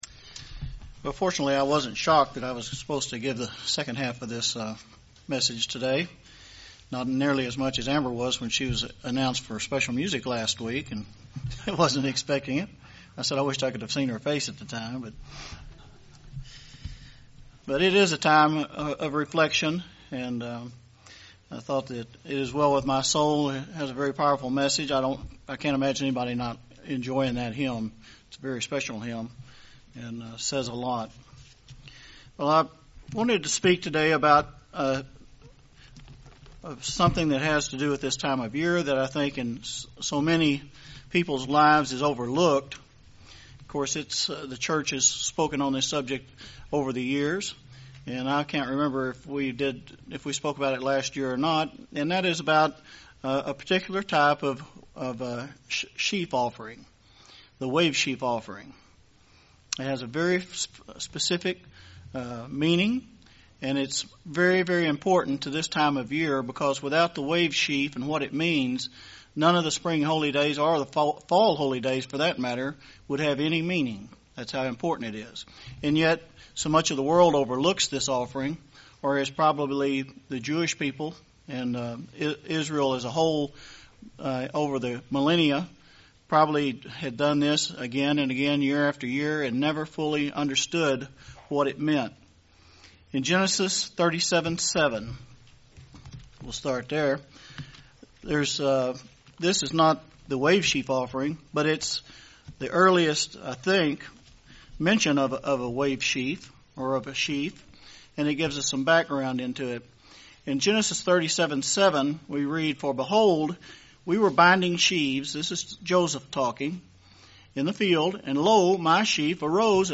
A sermon looking into the topic of the Wave Sheaf Offering.